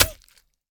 sounds / mob / bee / sting.ogg
sting.ogg